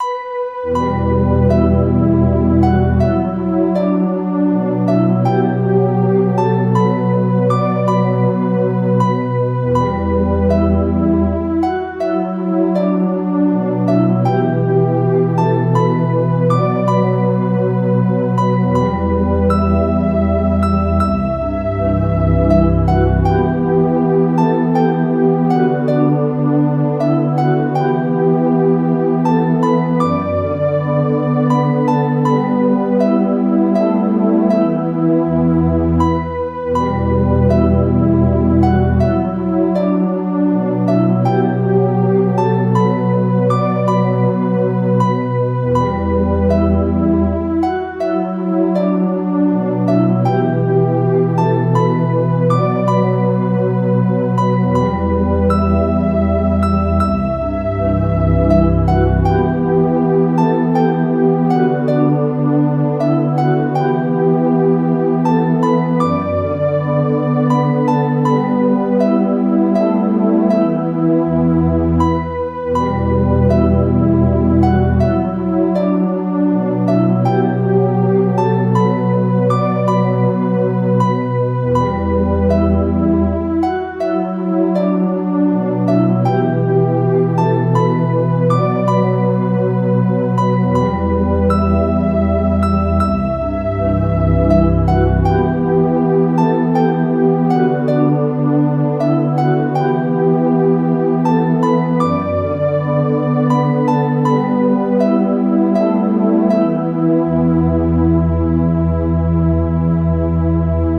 fenian.mid.ogg